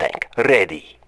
Modified Lasher Tank voice lines